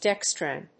/ˈdɛˌkstræn(米国英語), ˈdeˌkstræn(英国英語)/